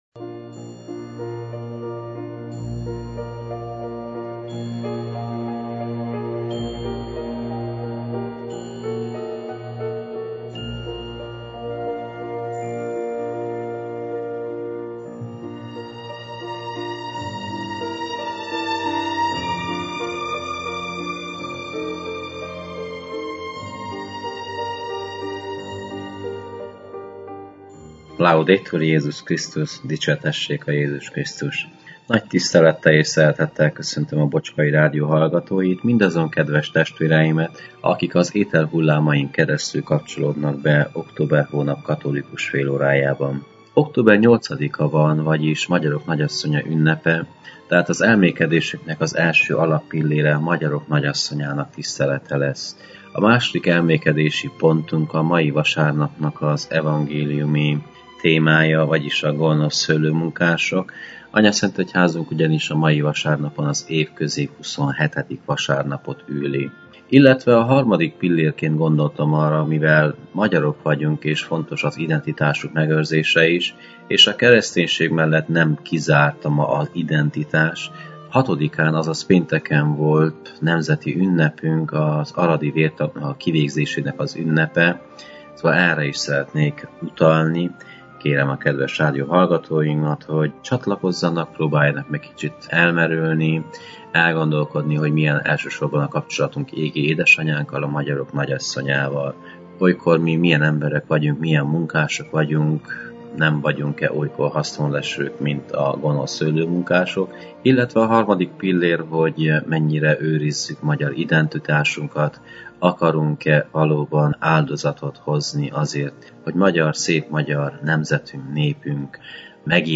a clevelandi Szent Imre Katolikus Templomból.